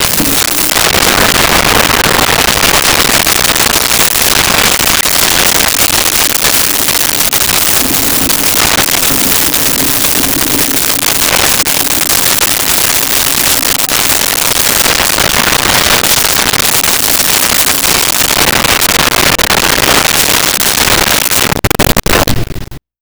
Bath Water Drain
Bath Water Drain.wav